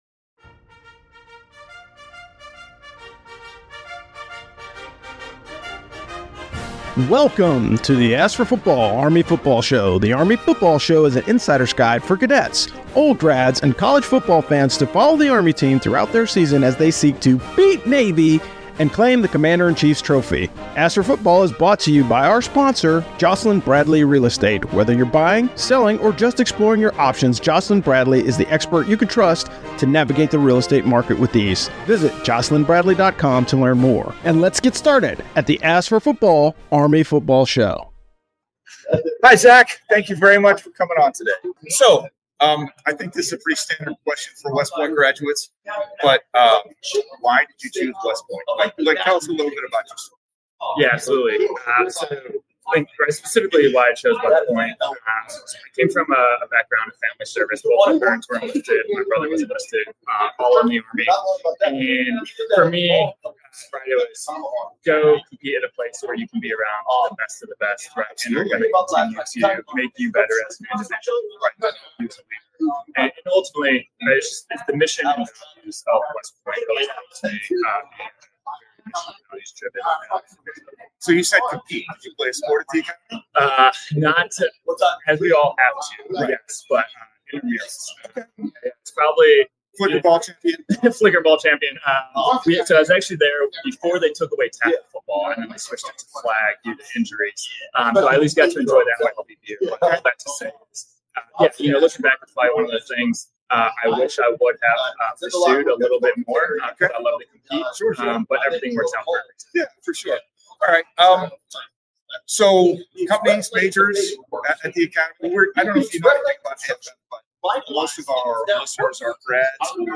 The AFF Crew went to Baltimore to participate in Media Row this week. We did a whole series of interviews, learning a lot and talking a lot about the Army-Navy Game. Fair warning: we experienced a bit of discovery learning through this process, so the audio quality starts a little rough.